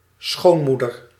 Ääntäminen
IPA: [ˈɑnopːi]